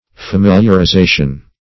Familiarization \Fa*mil`iar*i*za"tion\, n.